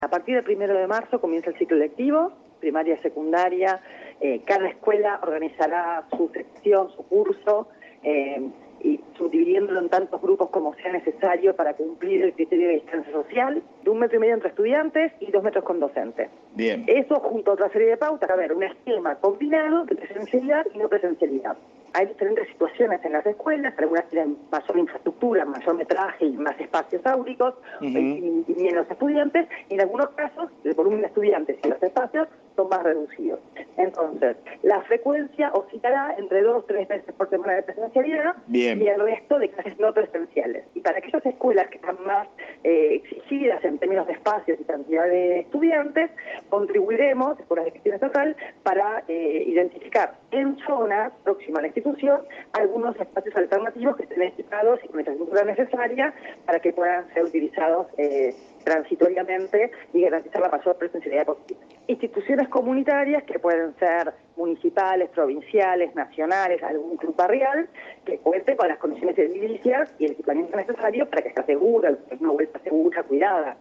Declaraciones de Agustina Vila en Radio AM Provincia